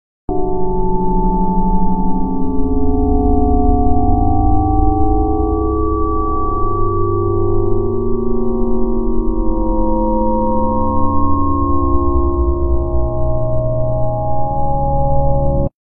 1150Hz.mp3